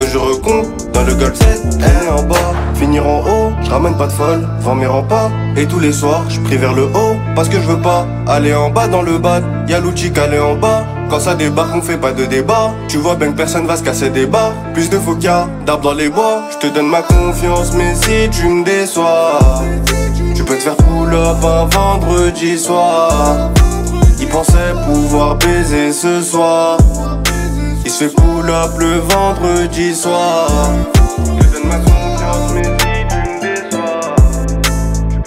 Catégorie Rap / Hip Hop